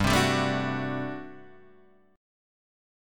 GM7sus2sus4 chord